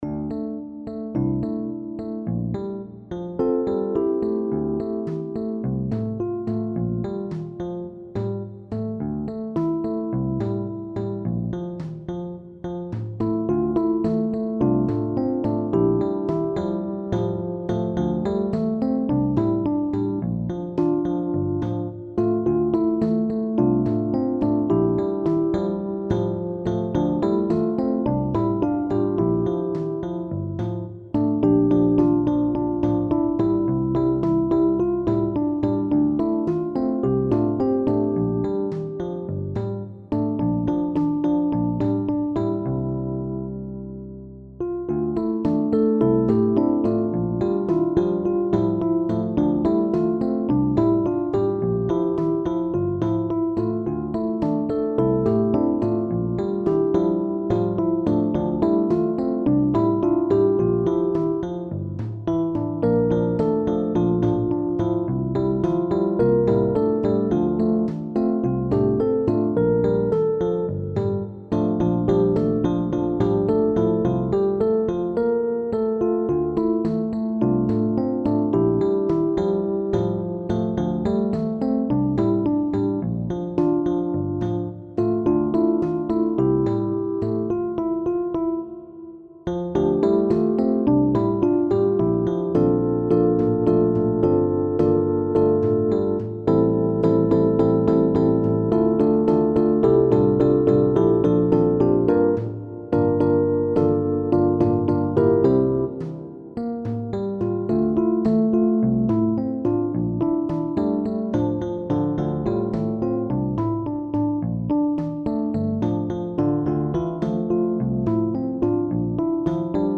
SSATB